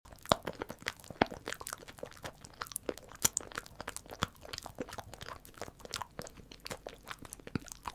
Wiener Dog Eating ASMR 🍖 Sound Effects Free Download